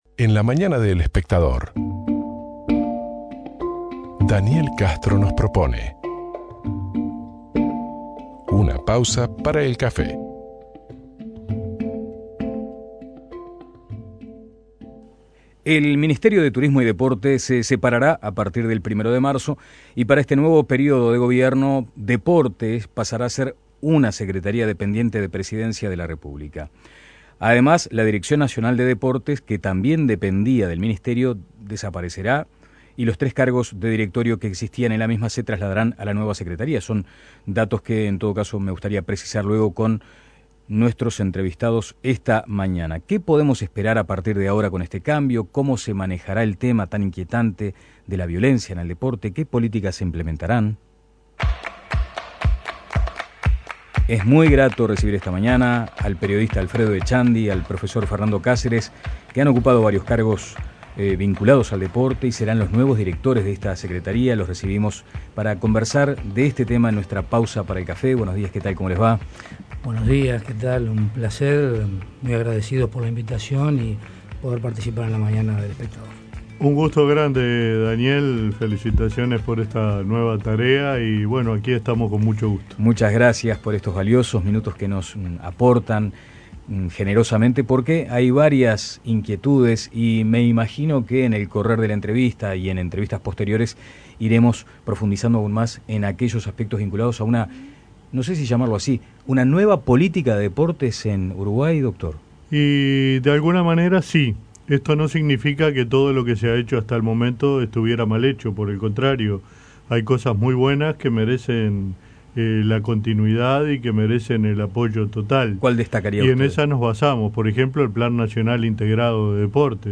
Descargar Audio no soportado En nuestra "pausa para el café"; recibimos a los nuevos directores de esta secretaría, el periodista Alfredo Etchandy y el profesor Fernando Cáceres, quien ha ocupado varios cargos de gobierno vinculados al deporte.